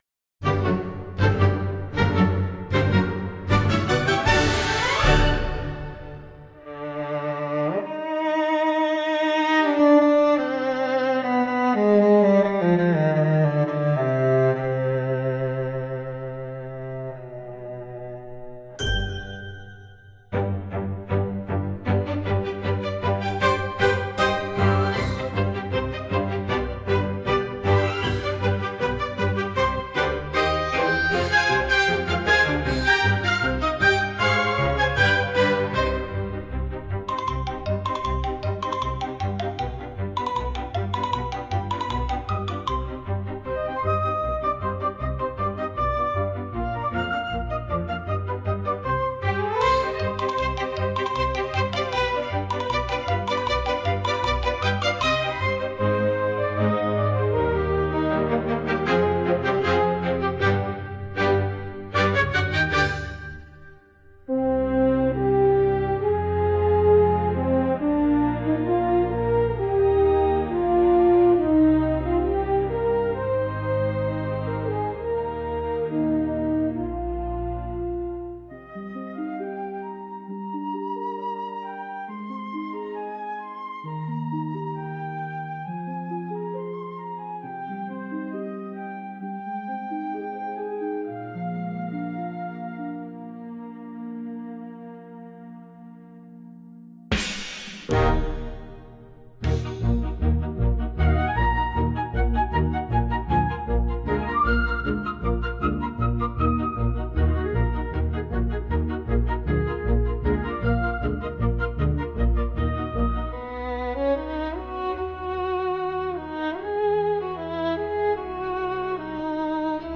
Instrumental / 歌なし
🌍 An elegant orchestral piece filled with grace and emotion.
気品あふれるオーケストラサウンドで描かれた、永遠に続く舞いの物語。
優雅な旋律とドラマチックな展開が、新体操の演技や華やかなステージを美しく彩ります。